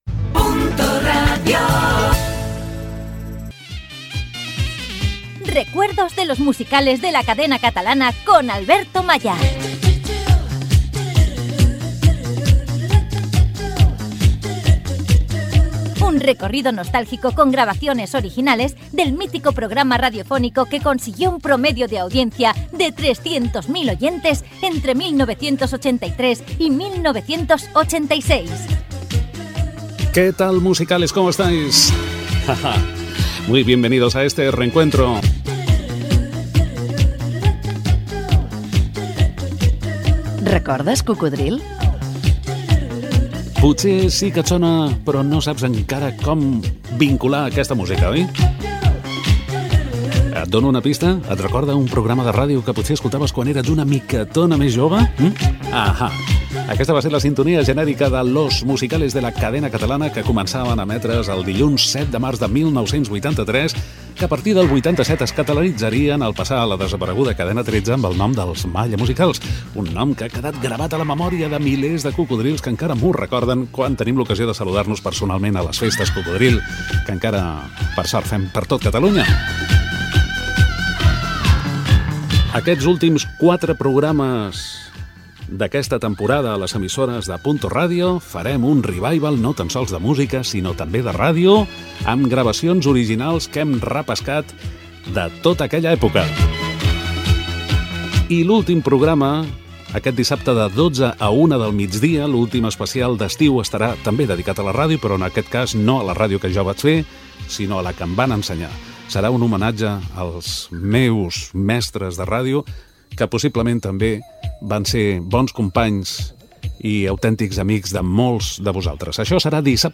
Careta del programa, presentació del programa recordant l'origen del programa i avançant els temes que protagonitzaran els propers espais.
Musical